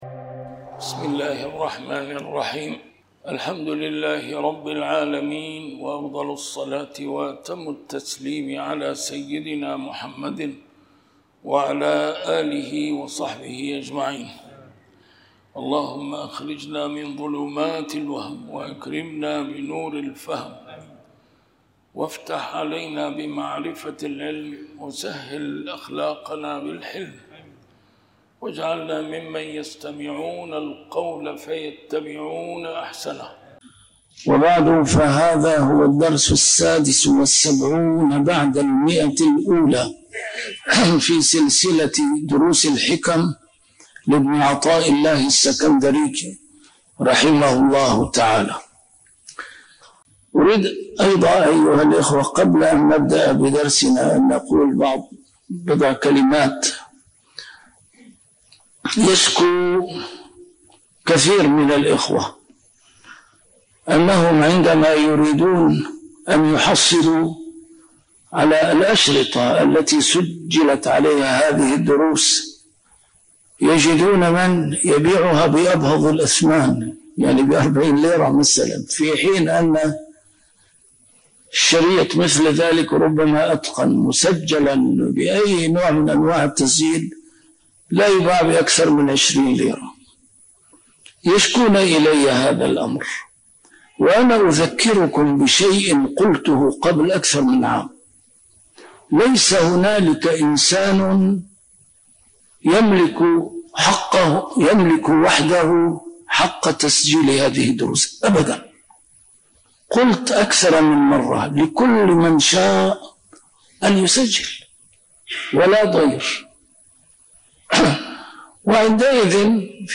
A MARTYR SCHOLAR: IMAM MUHAMMAD SAEED RAMADAN AL-BOUTI - الدروس العلمية - شرح الحكم العطائية - الدرس رقم 176 شرح الحكمة 151